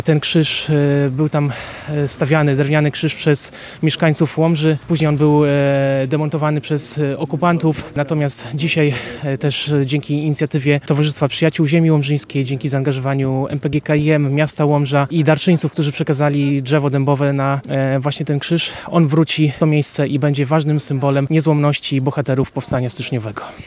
Głównym punktem uroczystości było podniesienie i poświęcenie krzyża na „Mokrej Łączce” – miejscu kaźni bohaterów Powstania.
Wiceprezydent Łomży Piotr Serdyński podkreślił, że krzyż jest ważnym symbolem Powstania.